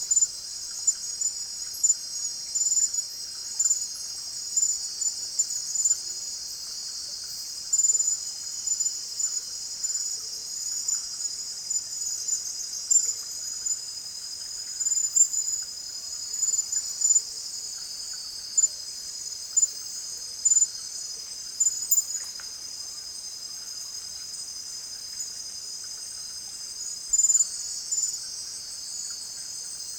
rainforest
bird-voices